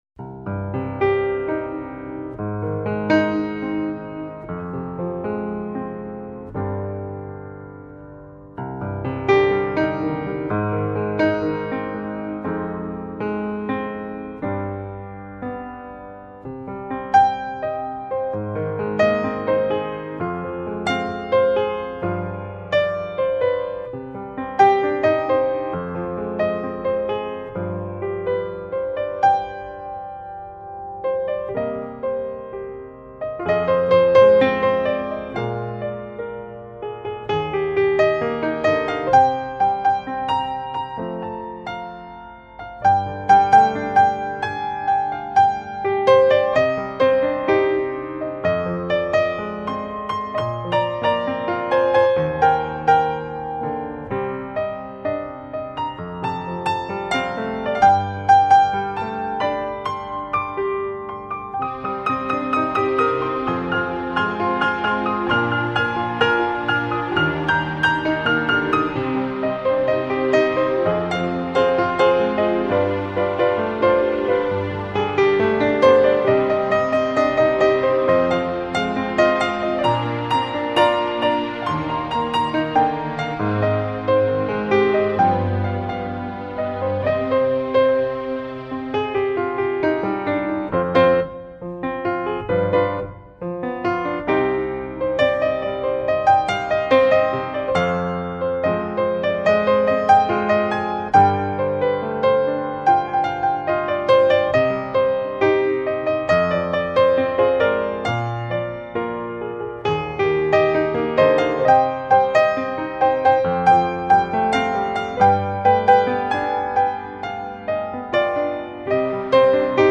HIT COLLECTION 至HIT流行精选